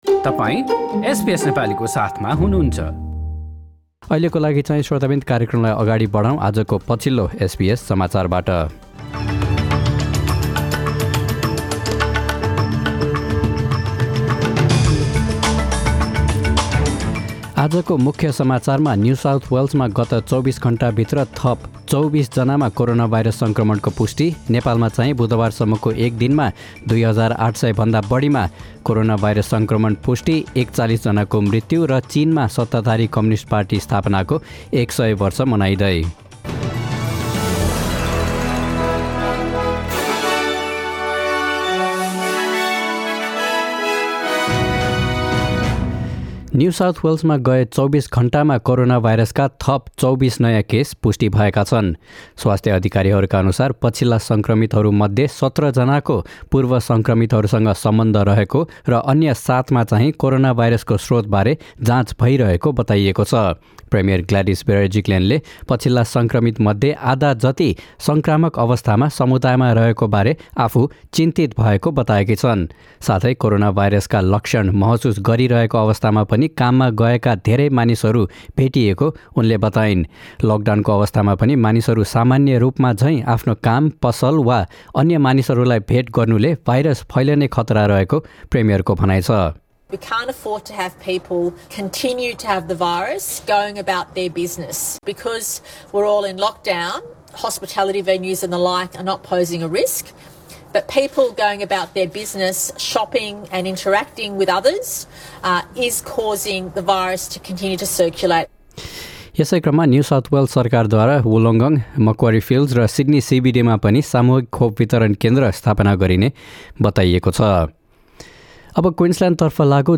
एसबीएस नेपाली अस्ट्रेलिया समाचार: बिहीबार १ जुलाई २०२१